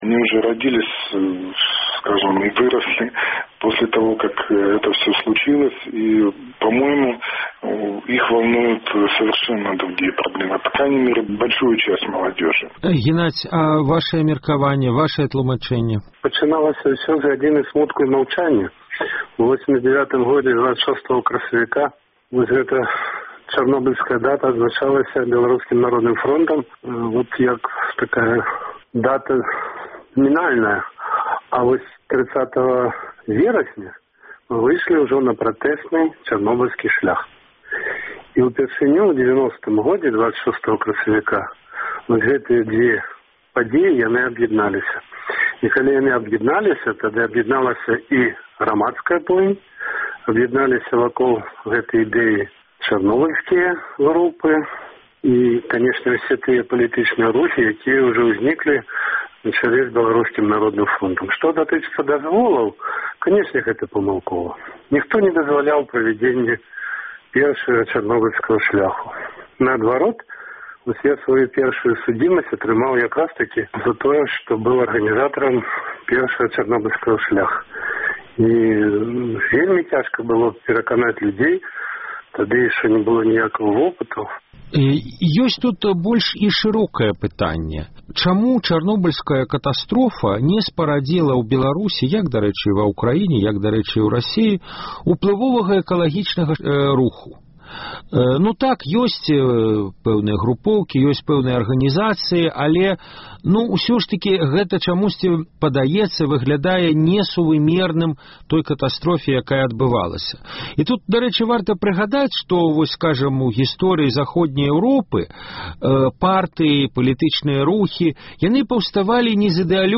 Штотыднёвы круглы стол экспэртаў і аналітыкаў на актуальную тэму.